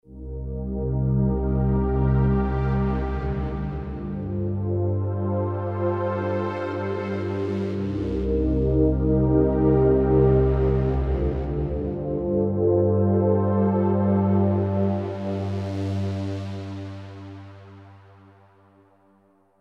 pad: muffled